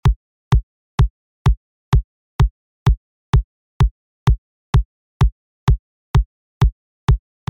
Fuel_128 – Drums_Kick_1
FUEL_-2-Fuel_128-Drums_Kick_1.mp3